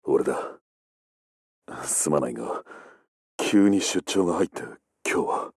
Guile sounds like he’s trying to be seductive.